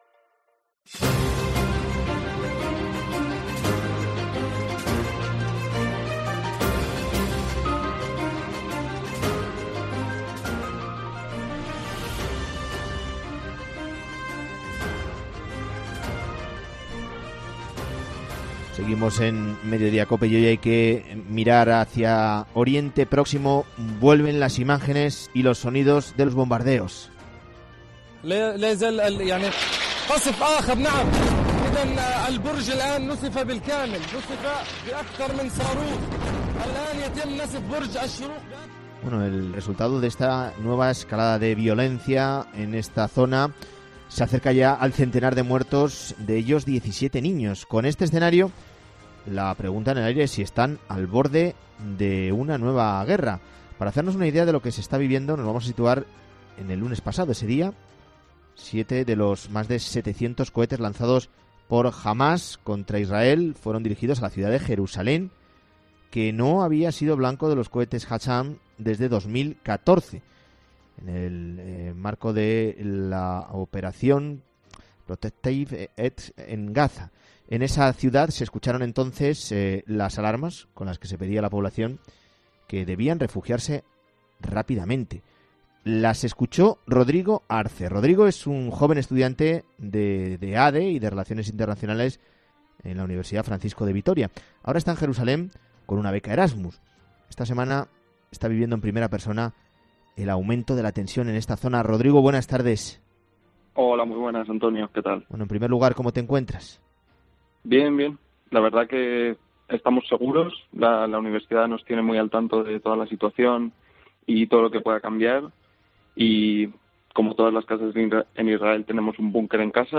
Vuelven las imágenes y lo sonidos de bombardeos en Oriente Próximo.